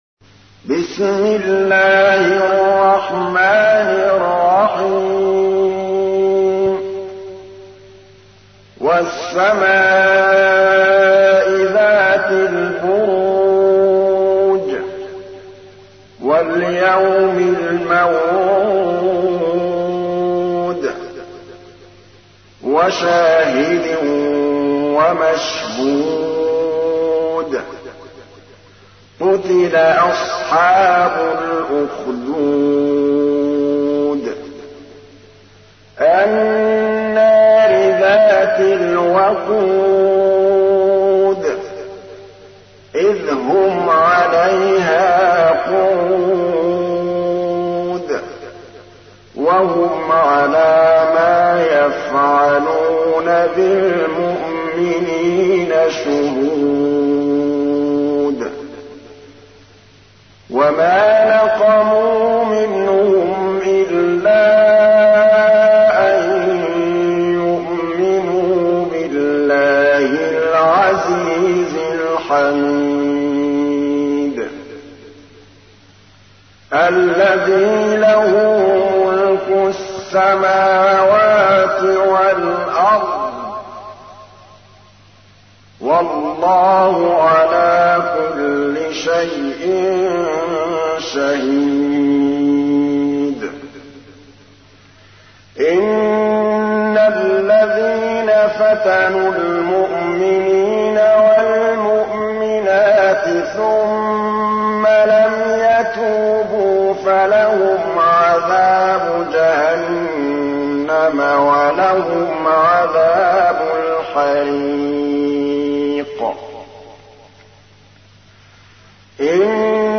تحميل : 85. سورة البروج / القارئ محمود الطبلاوي / القرآن الكريم / موقع يا حسين